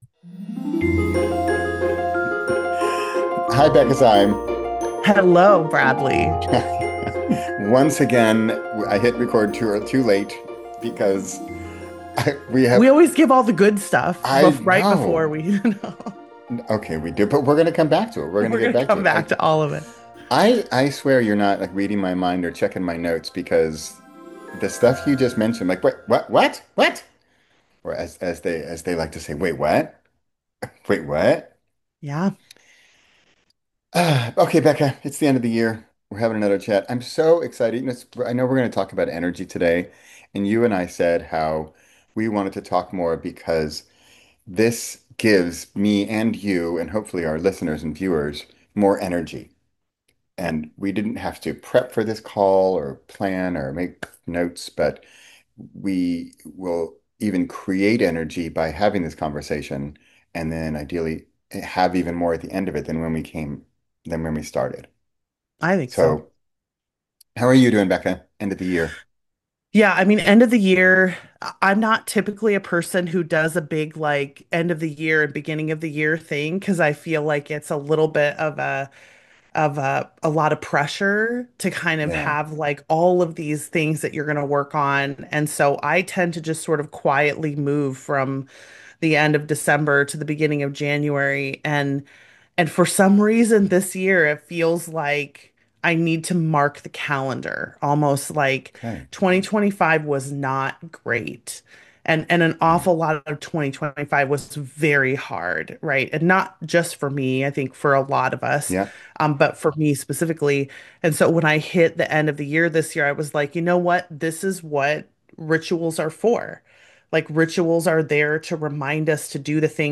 In this deep, end-of-year conversation